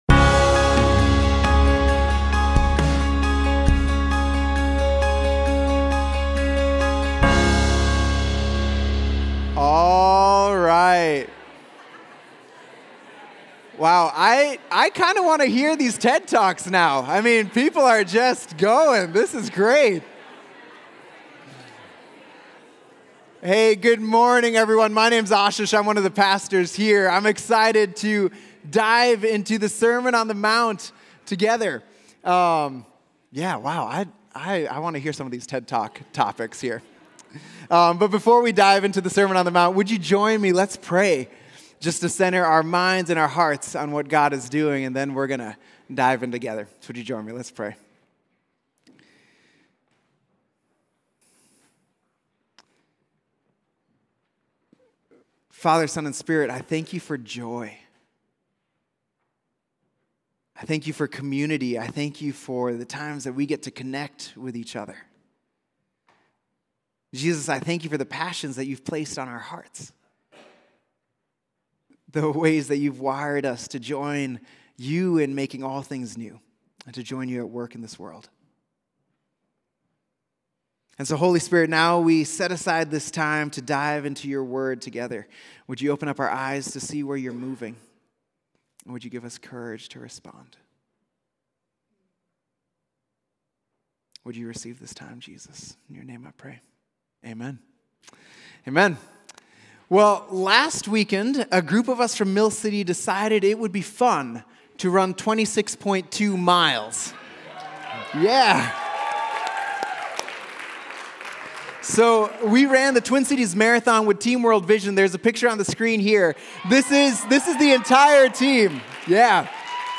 Mill City Church Sermons The Good Life: The People God is Forming Oct 14 2024 | 00:39:50 1x 00:00 / 00:39:50 Subscribe Share RSS Feed Share Link Embed